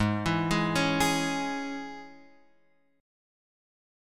G# Suspended 4th Sharp 5th